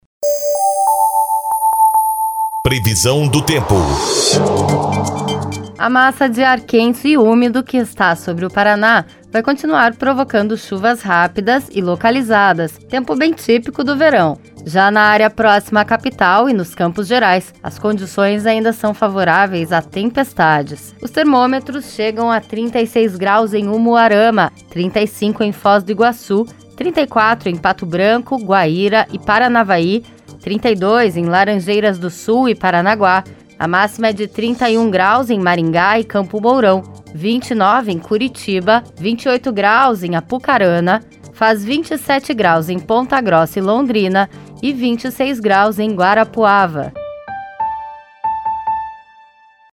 Previsão do tempo (18/01)